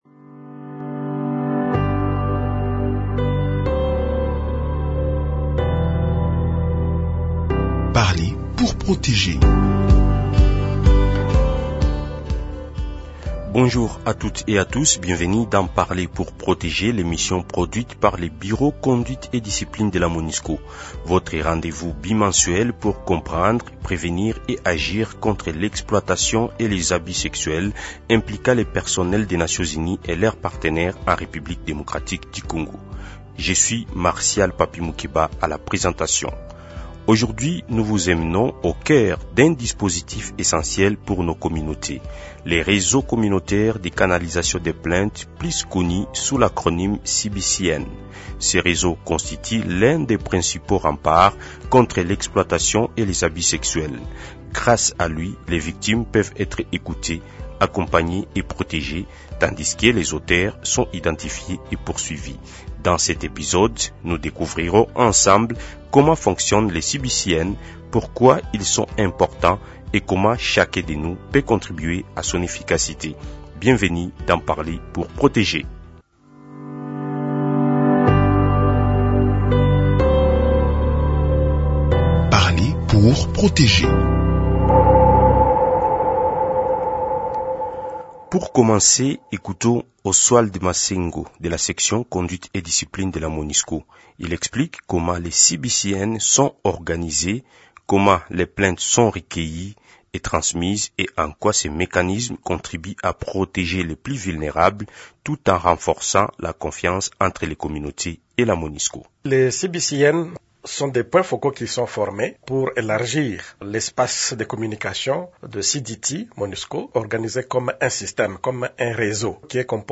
Le témoignage d’une victime , accompagnée et orientée grâce à ce réseau communautaire